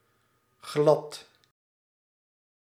Ääntäminen
IPA: /ɣlɑt/